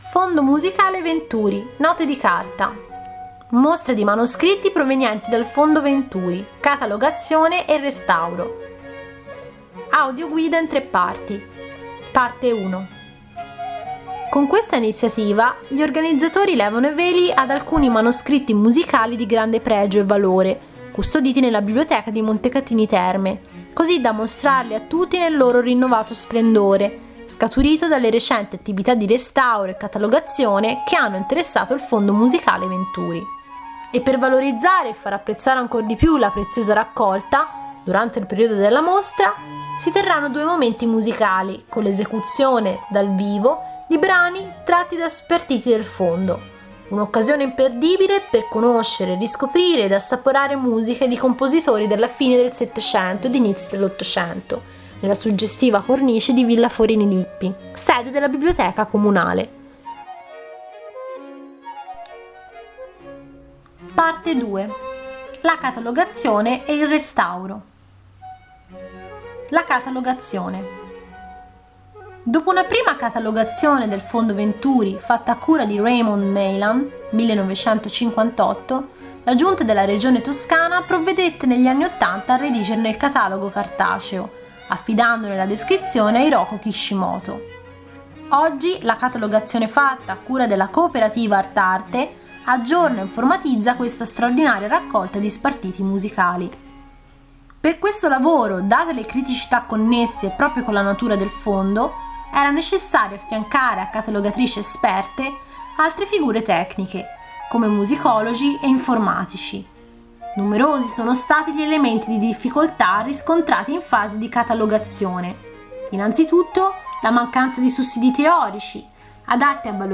Clicca sulla locandina per ascoltare l’audioguida della mostra